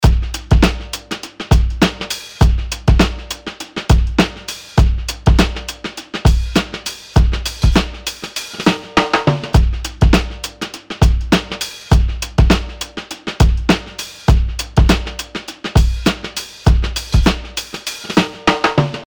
punchy_lo_fi_wet_df88d2b5a1.mp3